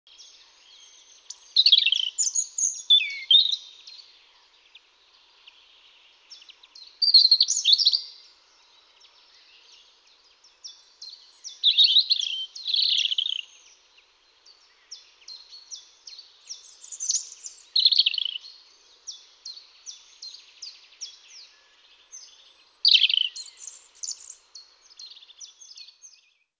le Rougegorge
Il chante toute l'année ou presque. Le chant d'hiver est différent du chant de reproduction, et émis par les deux sexes
Rougegorge_MN1.mp3